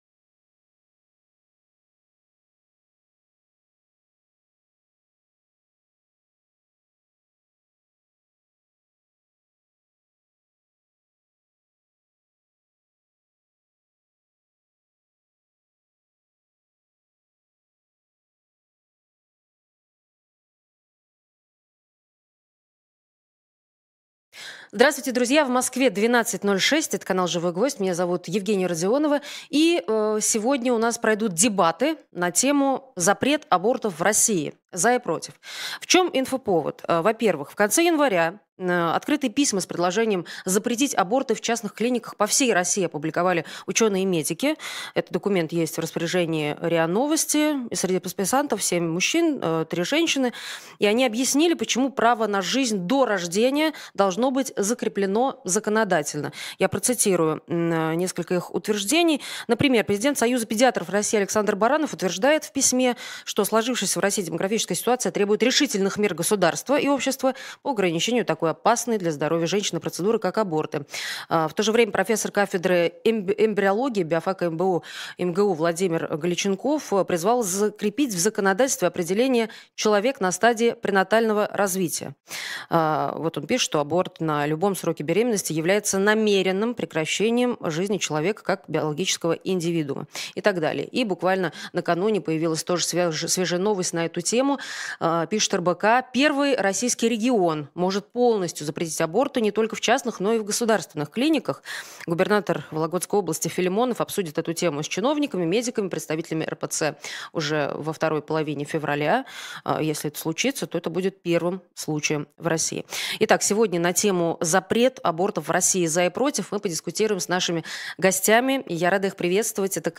«Дебаты» 07.02.2025